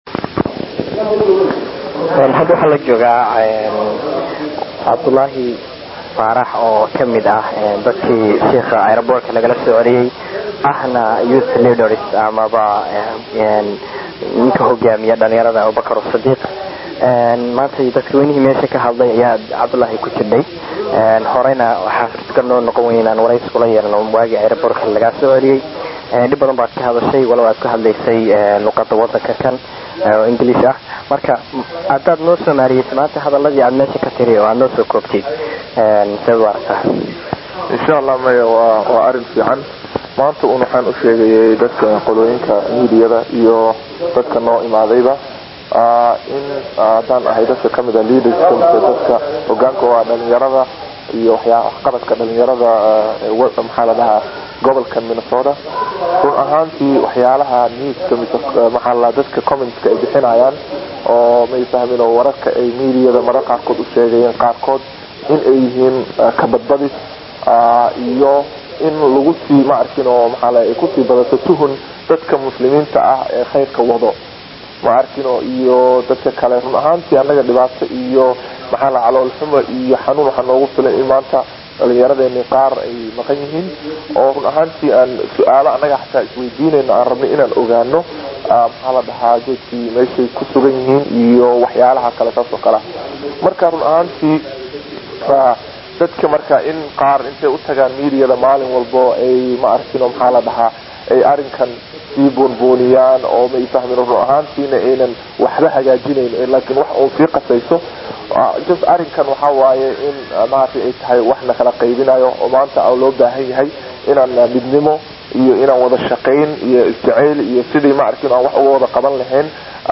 Shirkii kaddib waxaan la yeelanay waraysi.